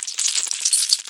PixelPerfectionCE/assets/minecraft/sounds/mob/spider/death.ogg at mc116
death.ogg